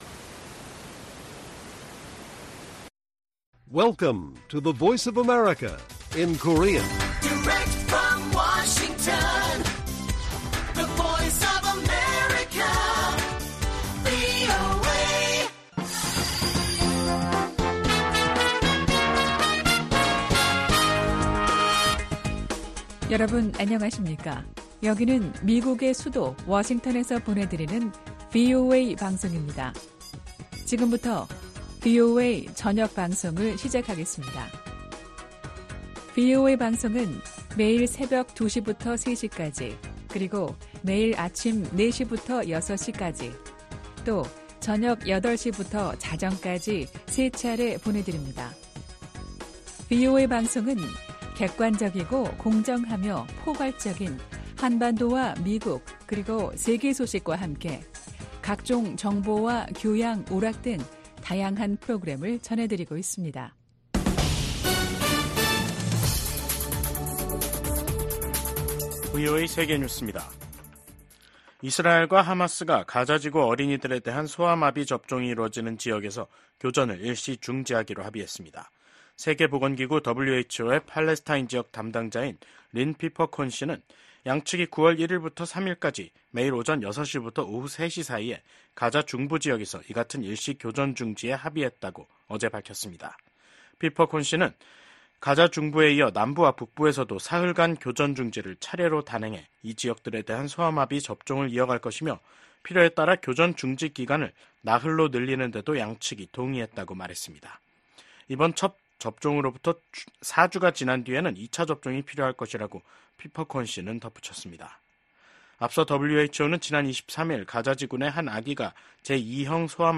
VOA 한국어 간판 뉴스 프로그램 '뉴스 투데이', 2024년 8월 30일 1부 방송입니다. 북한 해군 자산들이 국제해사기구(IMO) 자료에서 사라지고 있습니다.